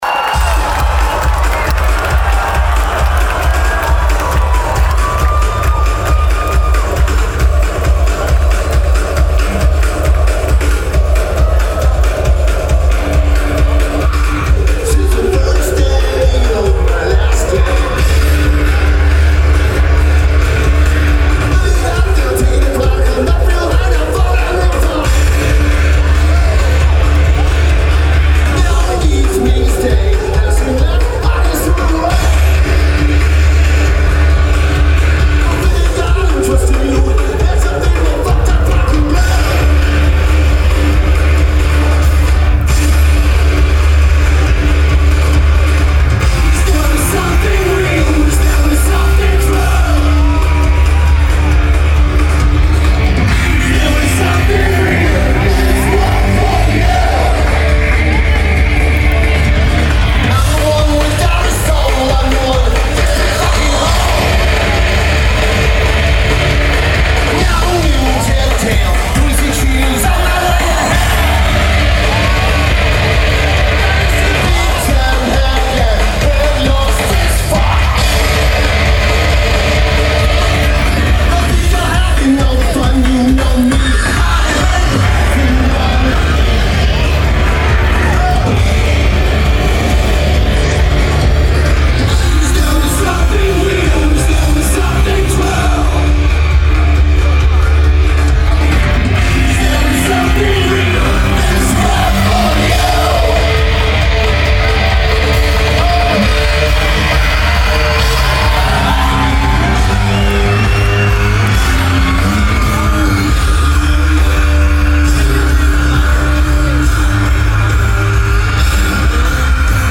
The Joint
Las Vegas, NV United States
Lineage: Audio - AUD (Pixel XL)